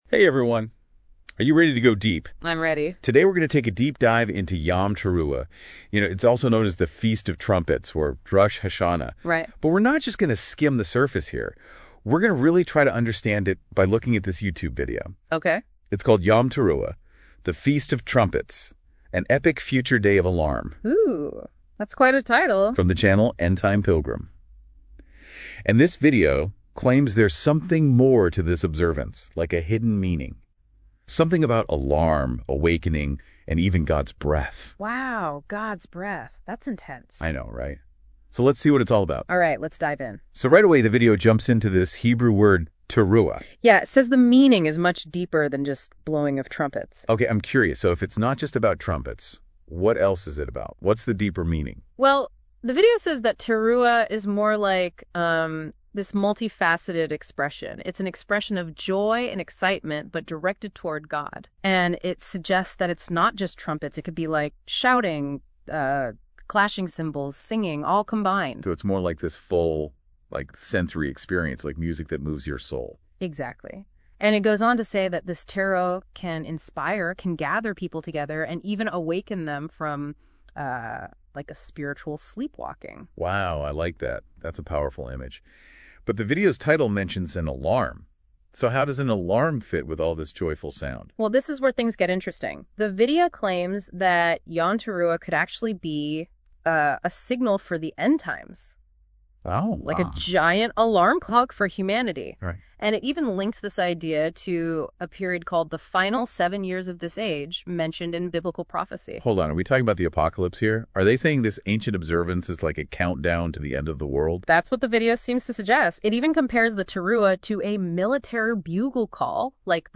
The AI NotebookLM two-personmp3 AUDIO commentary on the video above.